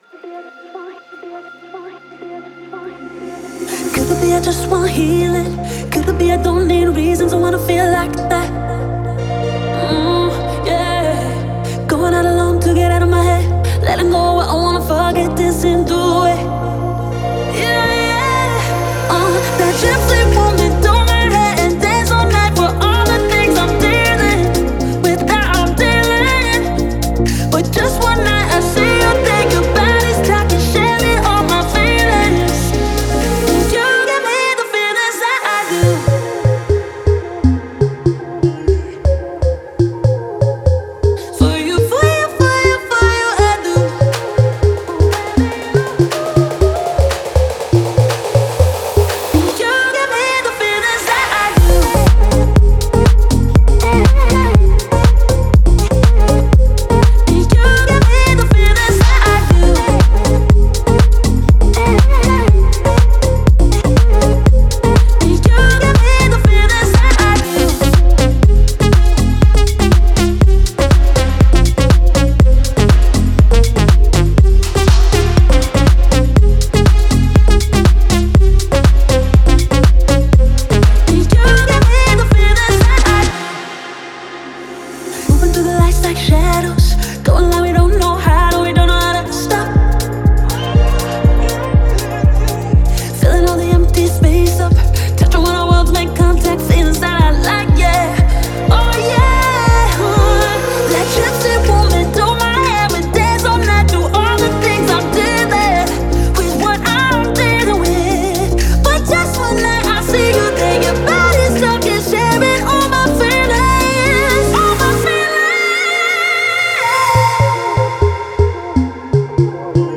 это энергичная поп-песня с элементами электронного звучания